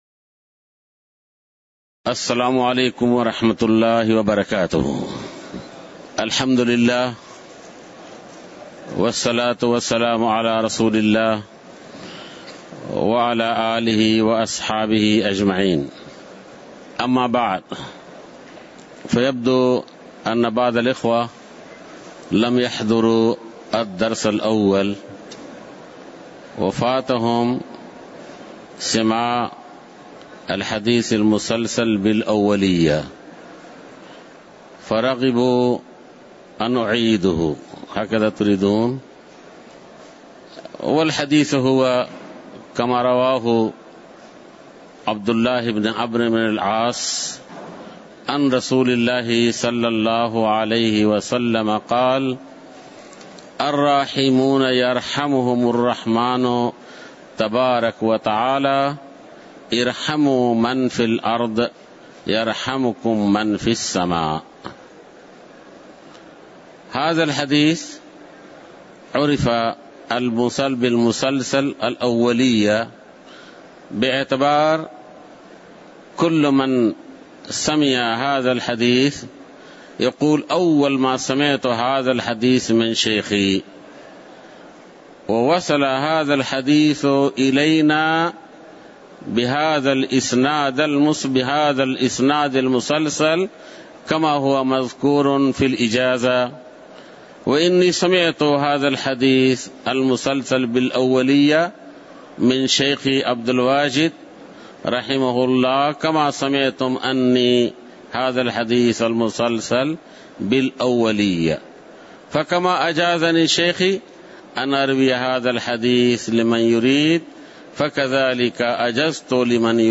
تاريخ النشر ١٥ ربيع الأول ١٤٣٥ المكان: المسجد النبوي الشيخ